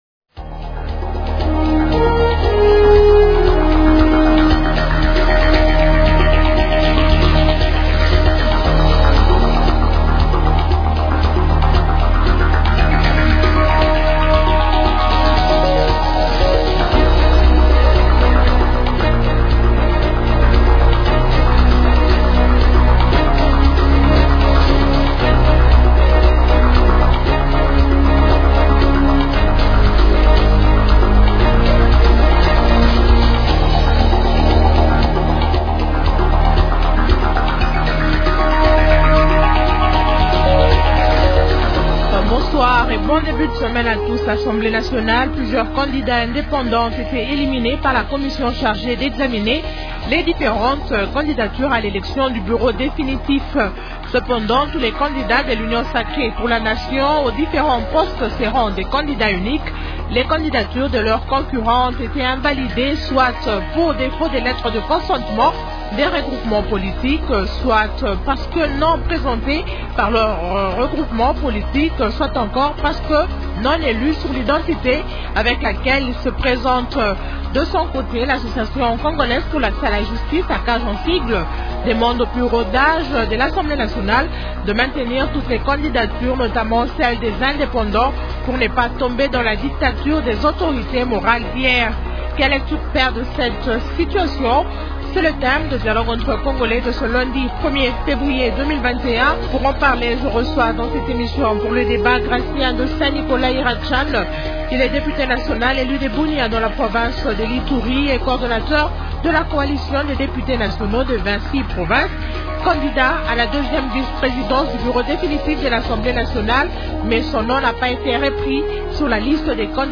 Question : -Quelle lecture faites-vous de cette situation ? Invités : -Gratien de Saint Nicolas Iracan, Député national élu de Bunia dans la province de l’Ituri.
-Professeur Tony Mwaba, Député national et cadre du parti politique UDPS.